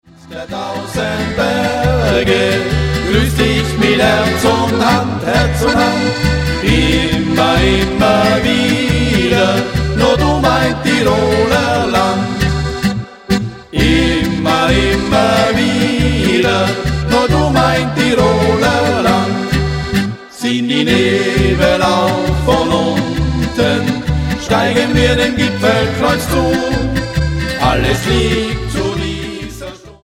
Genre: Volkstümliche Musik
Akkordeon, Gesang
Gitarre, Gesang
Kontrabass, Gesang